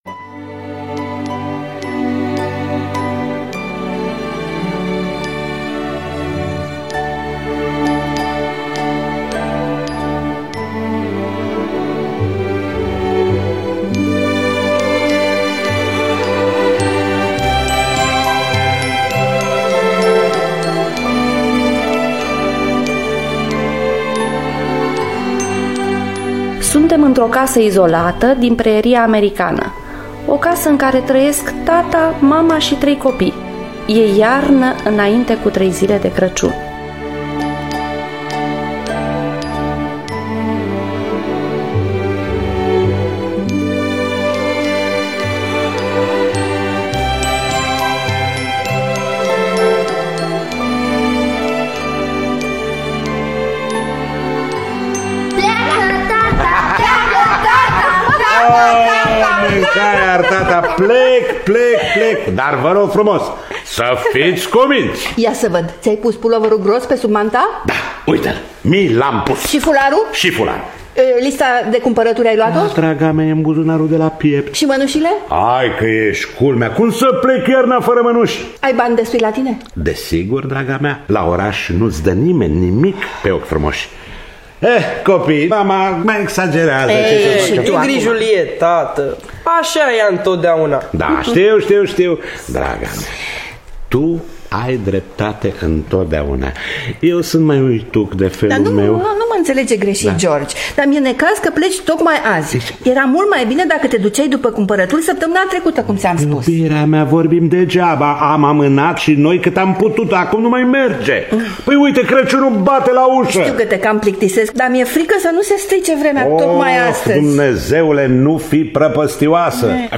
Crăciun sub zăpadă de Olive Thorne Miller – Teatru Radiofonic Online